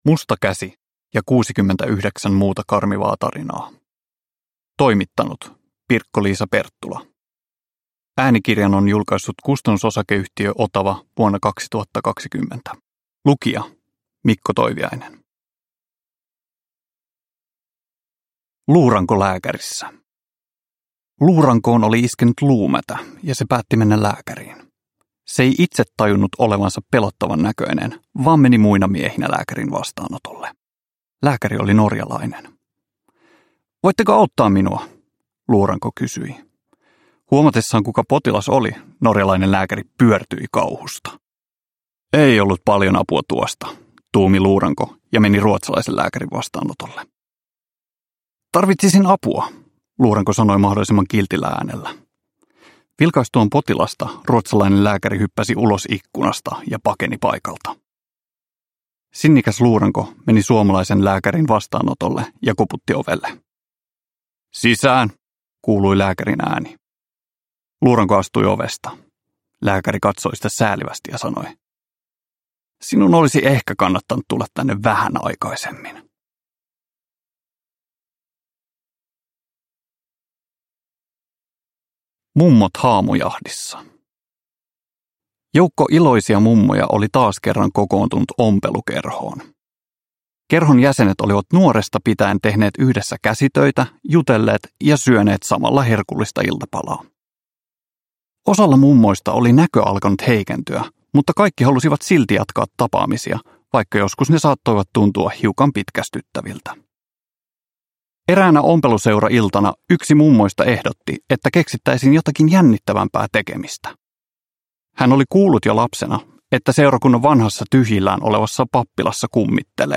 Musta käsi ja 69 muuta karmivaa tarinaa – Ljudbok – Laddas ner